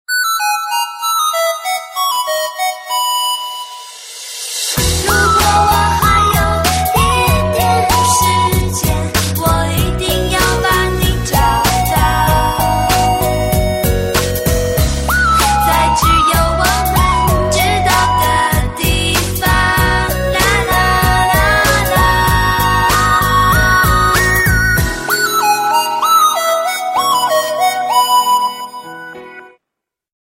С вокалом